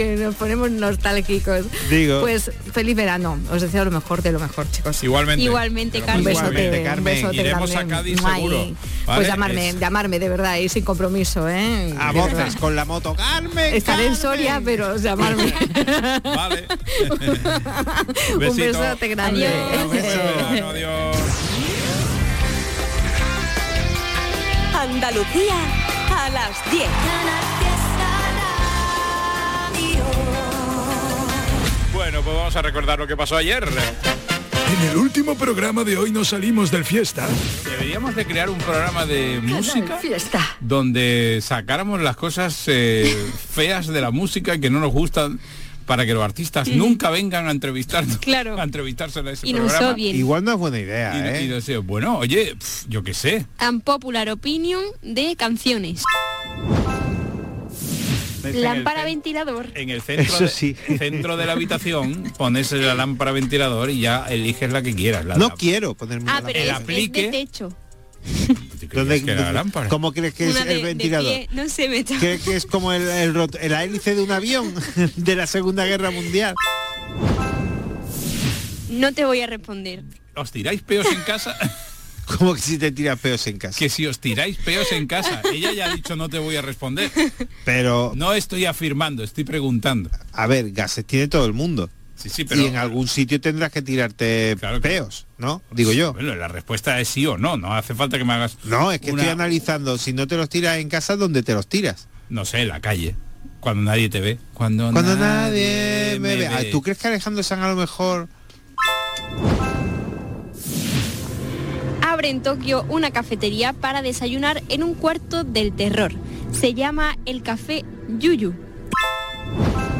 Buena música, humor y alguna que otra reflexión para organizarte tus cosas. Canal Fiesta te ofrece un programa nocturno de noticias y curiosidades muy loco. Un late radio show para que te quedes escuchando la radio hasta que te vayas a dormir.